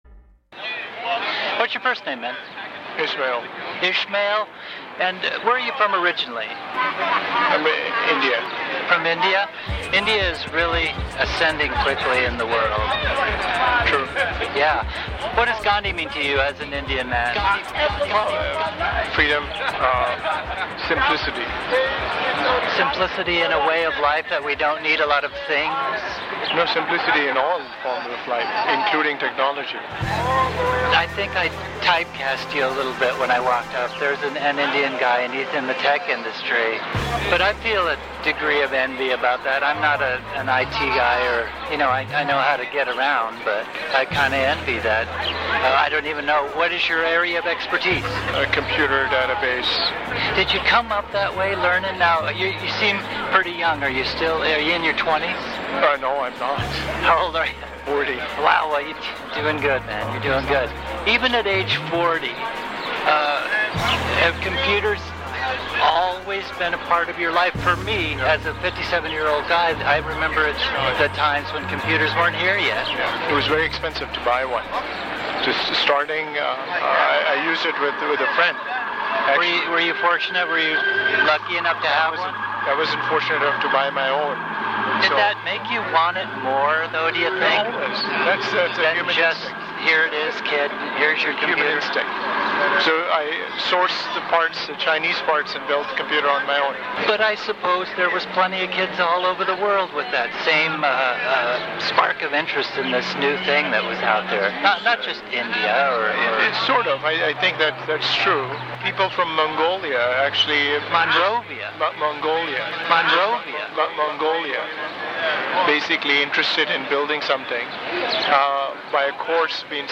Set: Man Playing 5-Gallon Pail in the Park (Rapper Joins In)